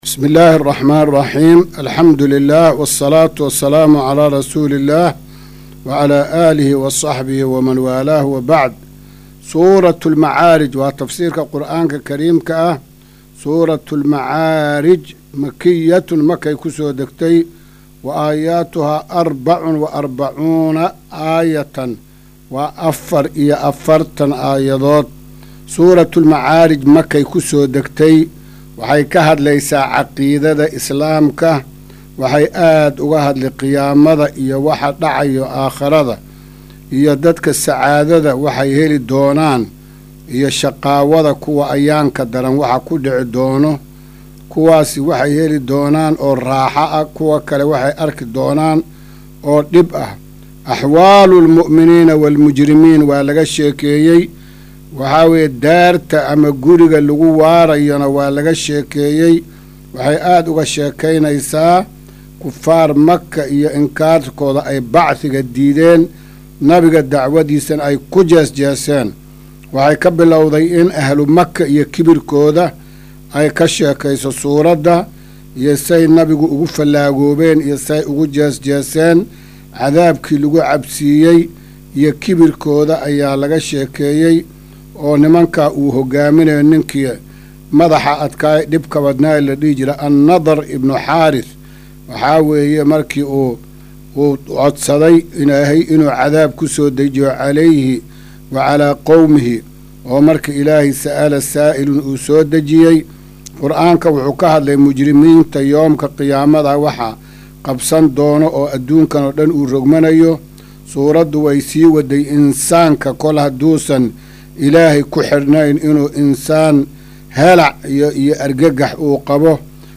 Maqal:- Casharka Tafsiirka Qur’aanka Idaacadda Himilo “Darsiga 272aad”